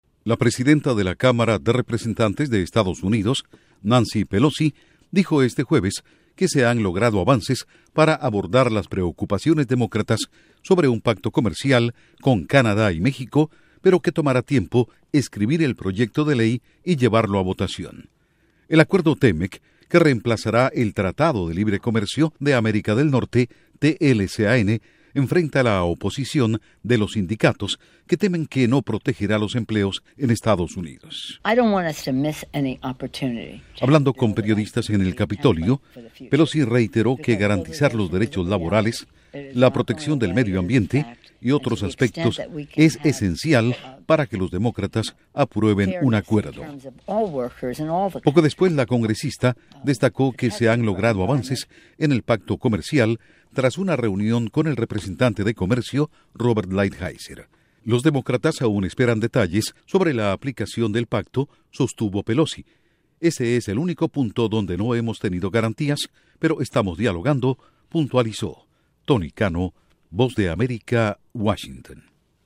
Duración: 1:12 Con declaraciones de Nancy Pelosi/Presidenta Cámara de Representantes